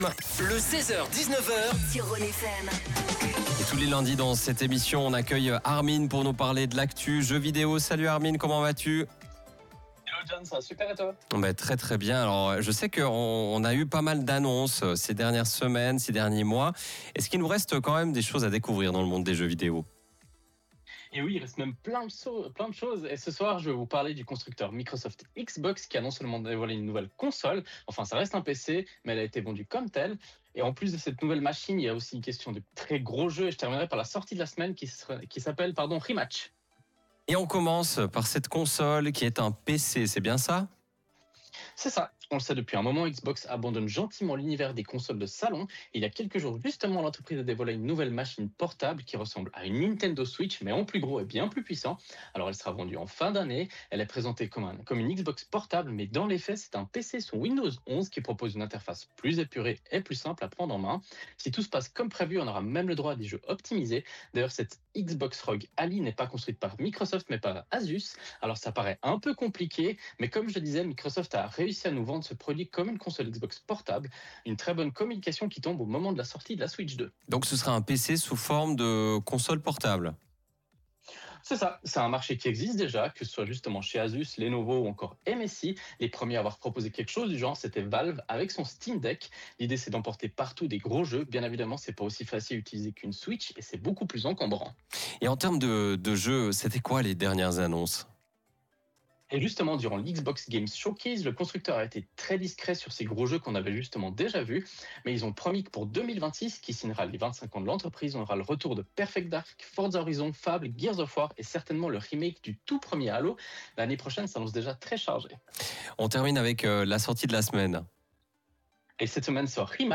Comme tous les lundis, nous avons la chance de présenter notre chronique gaming sur la radio Rhône FM. Une capsule gaming qui retrace l’actualité du moment.